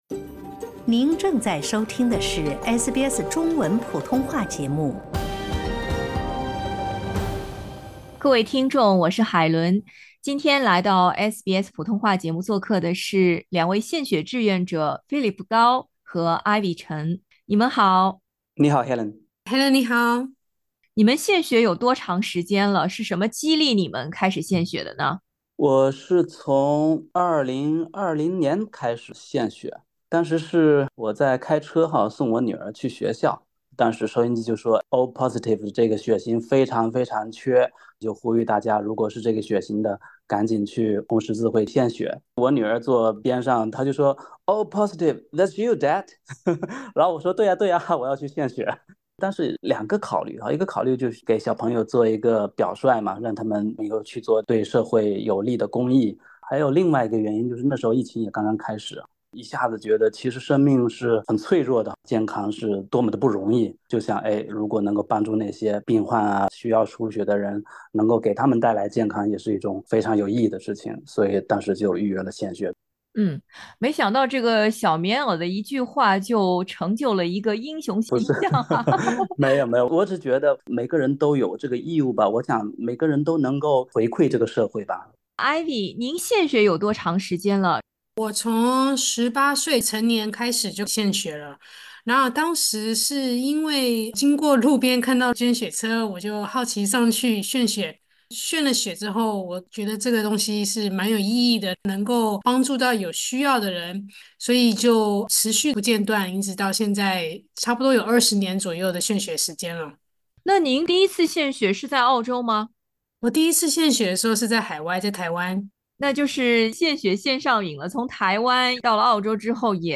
献血对身体有害吗？请点击音频，听听两位常规献血者怎么说。